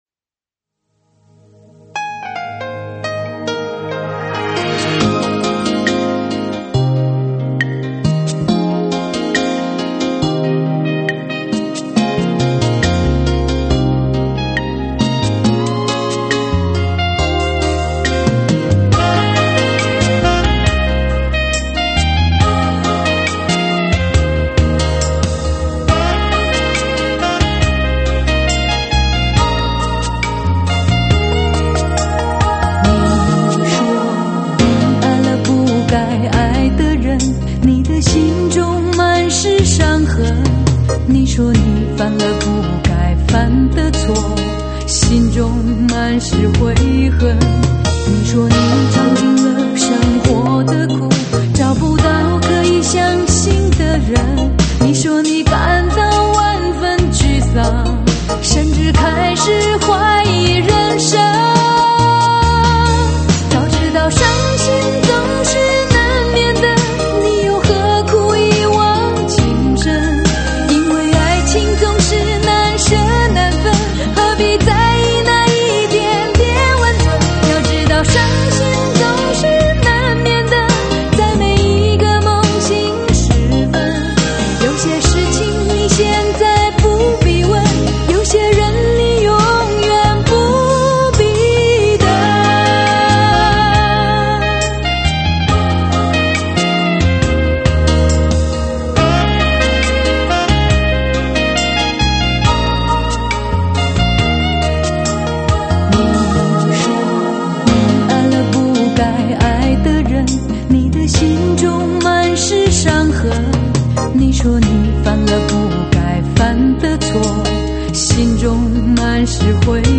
音场超级宽阔 人声真实 愉悦的类比音质感受 绝妙甜美的音色 极高的声音密度 极强的空气感